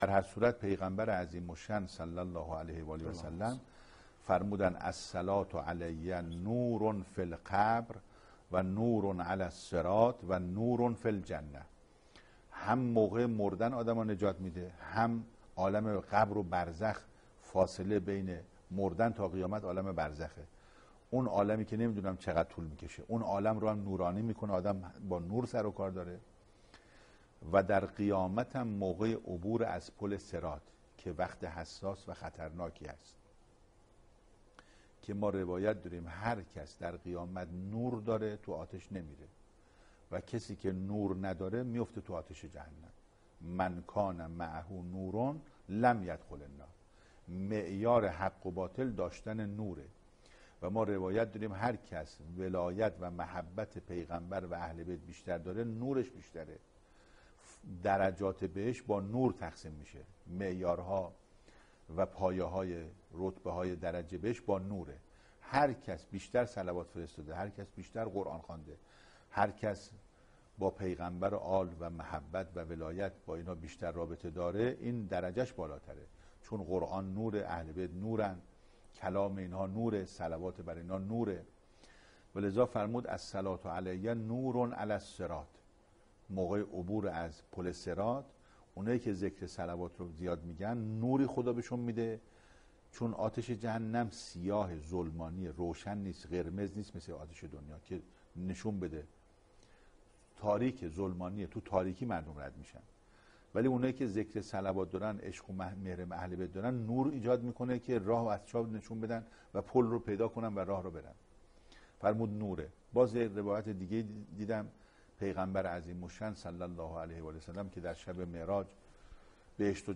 سخنرانی | تاثیر صلوات در دنیا و آخرت